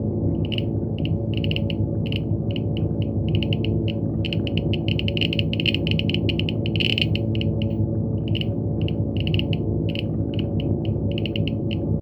nuclear-reactor-1.ogg